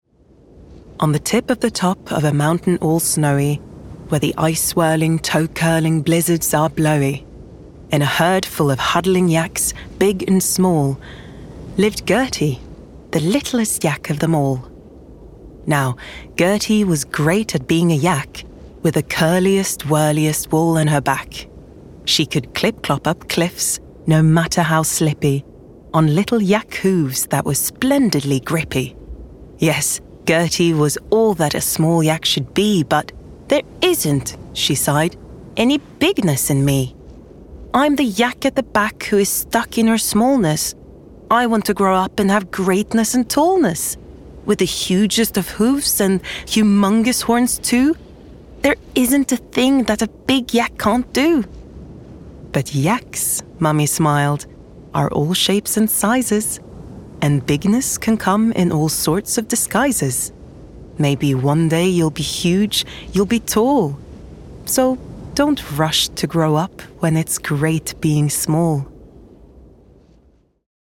Audio Book. Littlest Yak, Fun, Friendly, Engaging
Gender Female
Accent Norwegian Scandinavian Standard English R P Standard U S